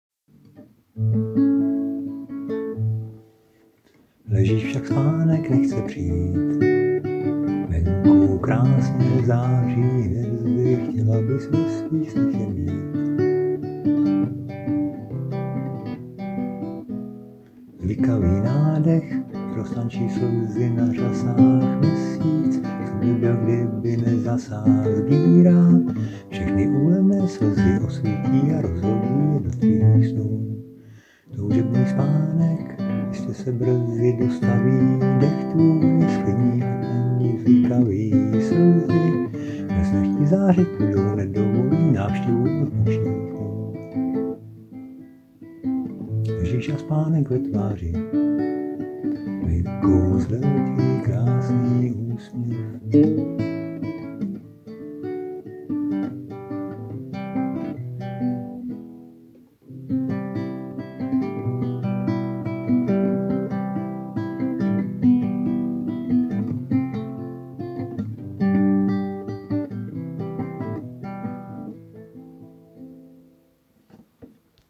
....Víš,když zpíváš jakkoliv ukolébavku dítěti,tak to není pěvecká soutěž,ale láskyplný projev sám o sobě a zvláště když tu píseň sám vytvoříš,děťátko to "ocení" tam někde v sobě,je to napojení v blízkosti......tož tak.....no a na učesanějším projevu se dá trocha zapracovat......takováta syrová,trochu ochraptělá a hlubší barva hlasu je vlastně mírně uklidňující ,hlubší hlasy mě osobně baví víc než "ječivé"soprány.....